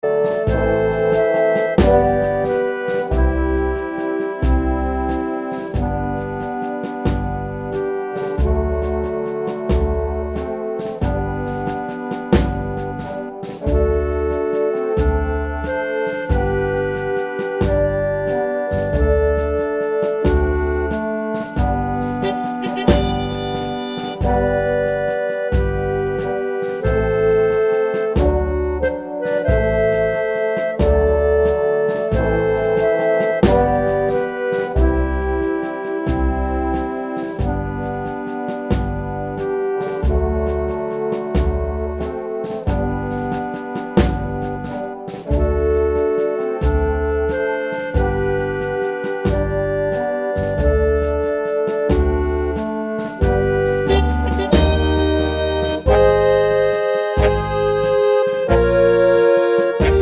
no Backing Vocals Oldies (Female) 2:42 Buy £1.50